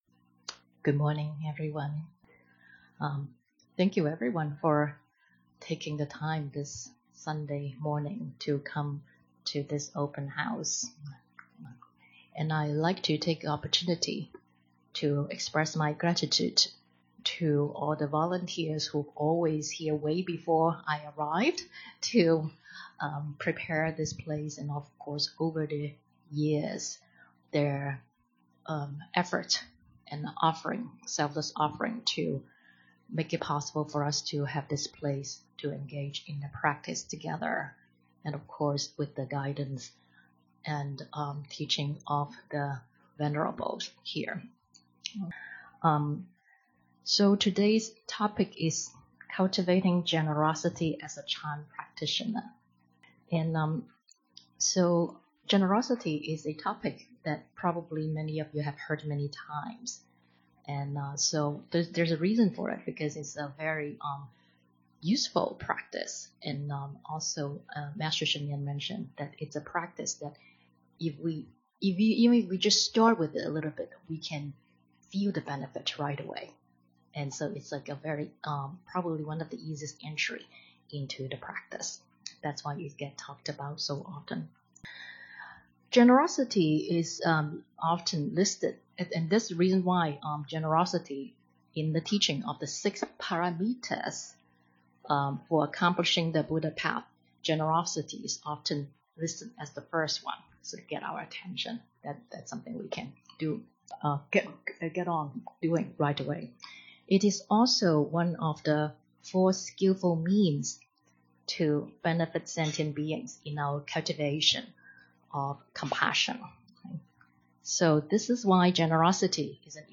This bilingual Dharma talk was given at Chan Meditation Center on March 25, 2018.